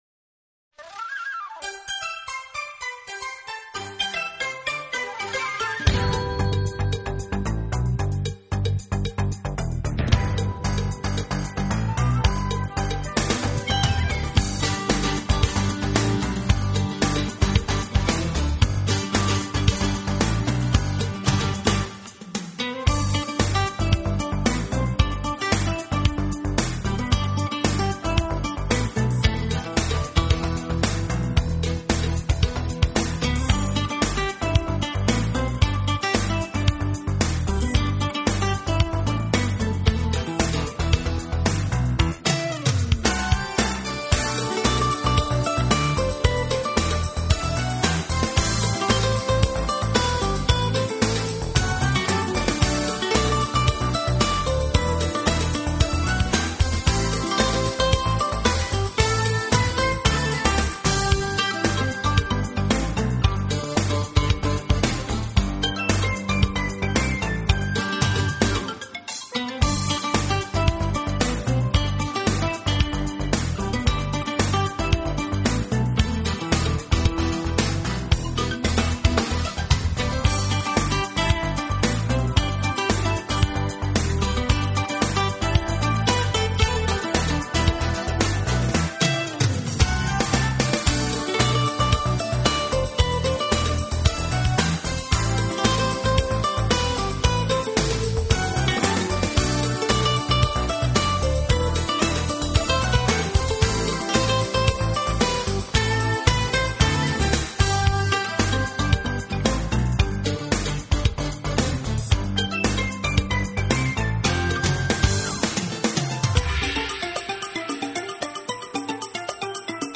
音乐类型：Smooth Jazz
guitar, keyboards